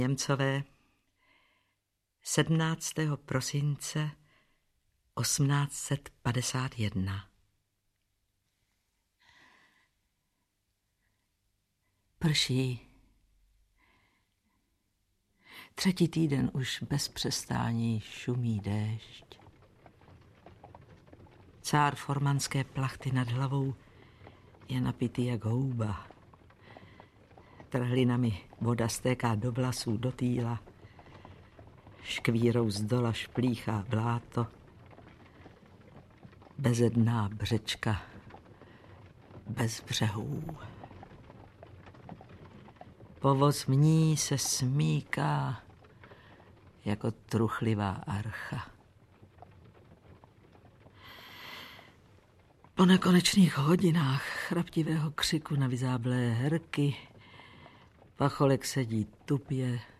Audiobook
Read: Vlasta Chramostová